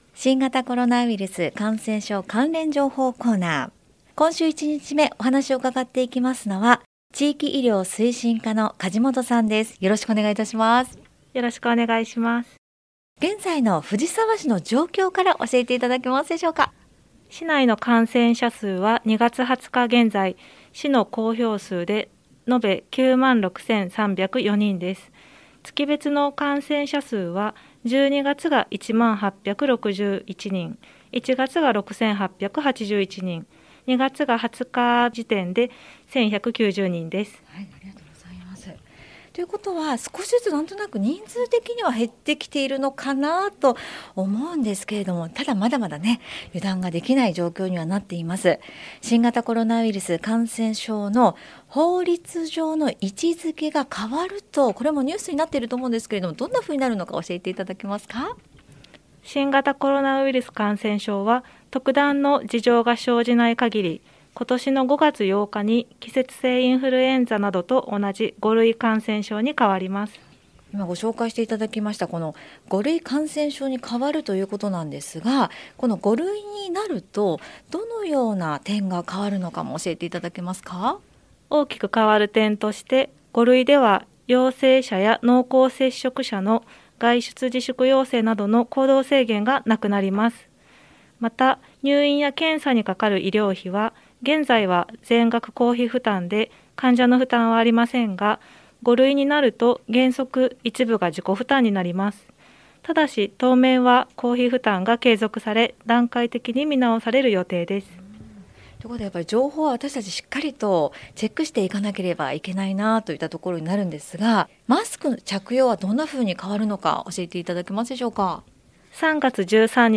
令和4年度に市の広報番組ハミングふじさわで放送された「新型コロナウイルス関連情報」のアーカイブを音声にてご紹介いたします。